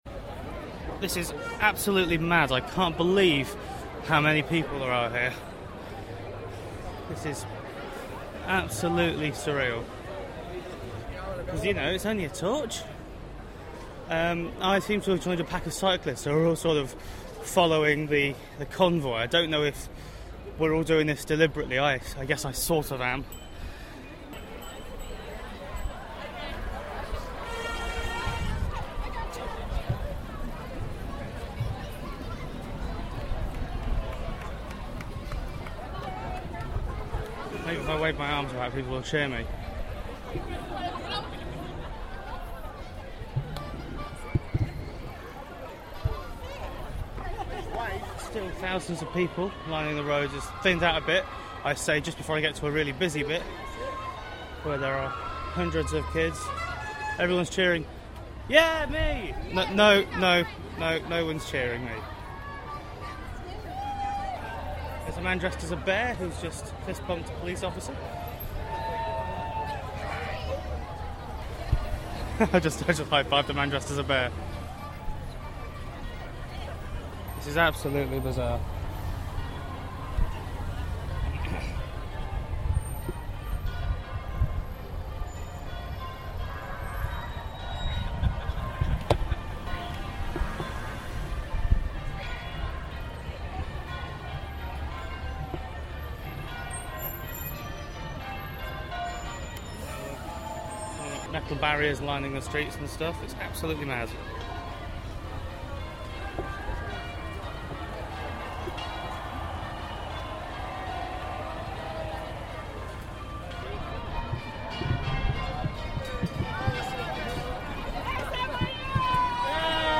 I took a Boris Bike to follow the Olympic Torch Convoy. Follow the adventure as I try to get the crowds to cheer me, and commentate on what happened on the stretch between Bankside and Bricklayers Arms.